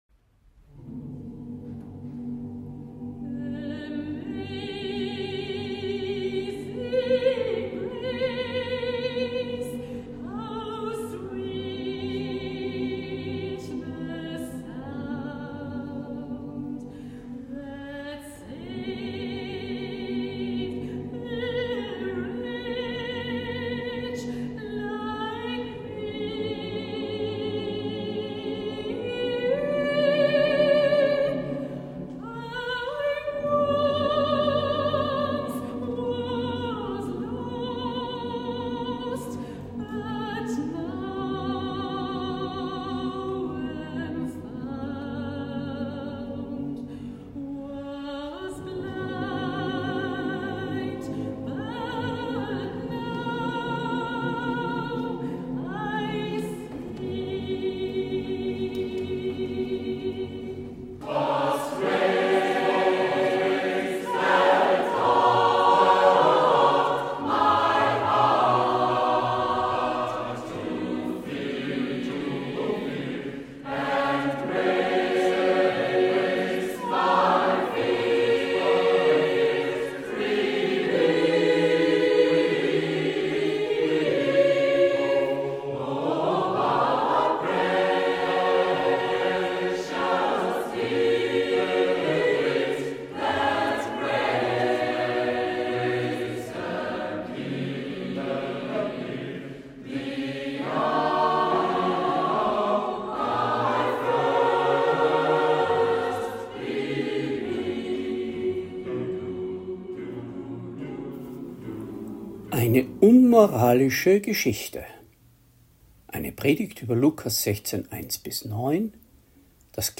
Predigt | NT03 Lukas 16,1-9 Der ungerechte Verwalter